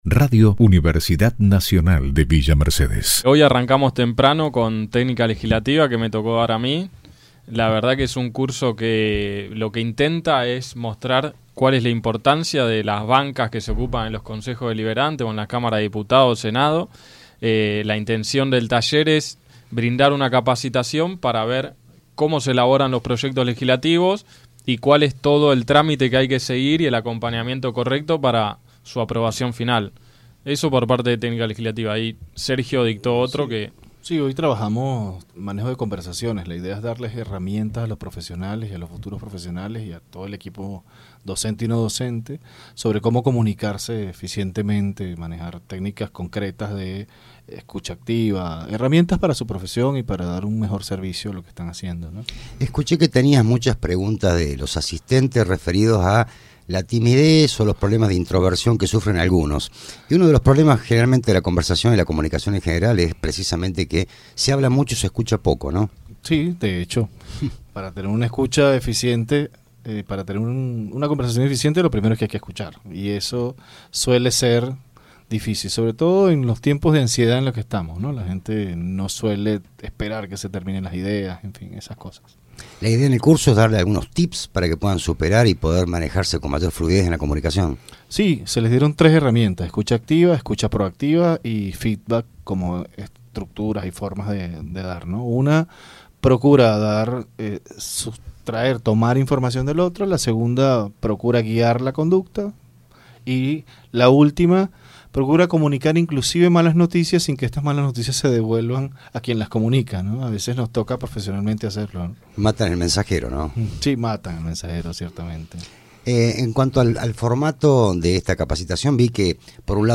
Los expositores dialogaron sobre las temáticas de sus conferencias con Radio UNViMe 93.7.